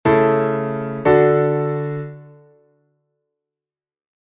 Cadencia Auténtica Imperfecta: un dos acordes está invertido.
DO-LA-MI-LA; RE-RE-FA-LA (V6-4 - I)